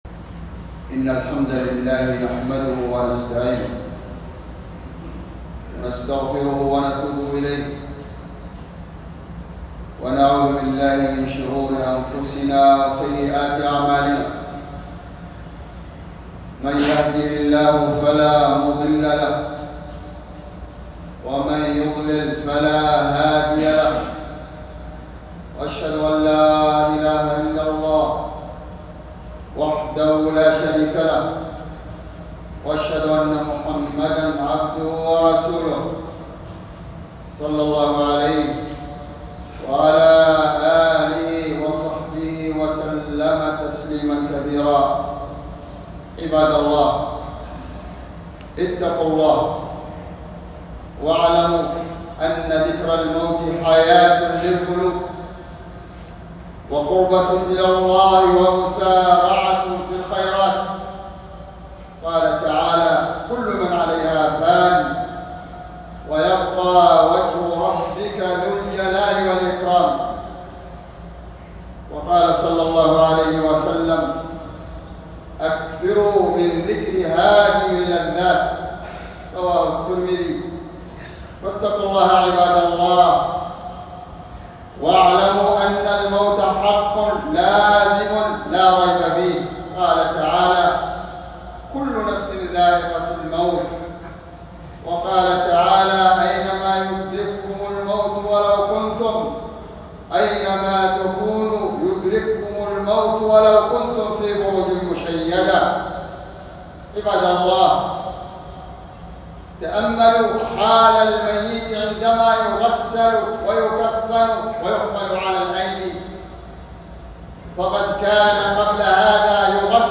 خطبة جمعة بعنوان موعظة عن الموت، والتنبيه على عدم التفريق بين قبر الرجل وقبر المرأة